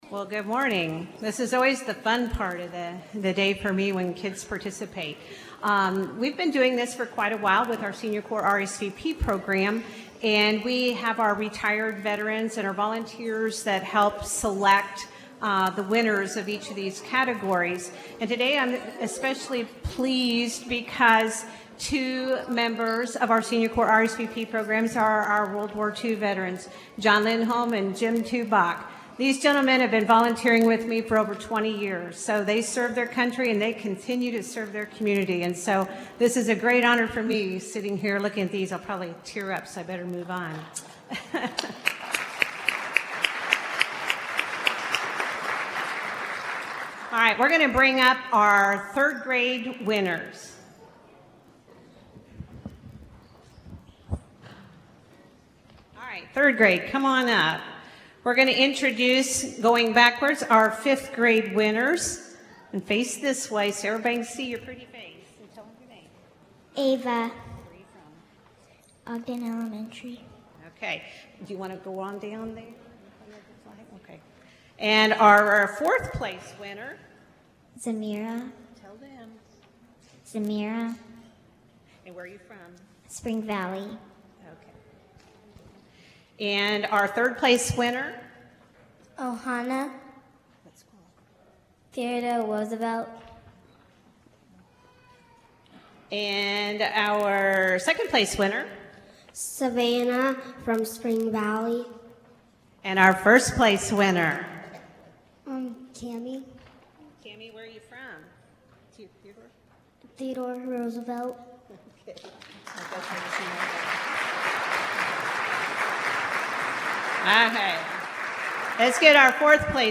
The Flint Hills Veterans Coalition put on their annual Veterans Day Parade and ceremony at Peace Memorial Auditorium.
Ceremony-essay-winners.mp3